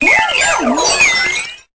Cri de Lampignon dans Pokémon Épée et Bouclier.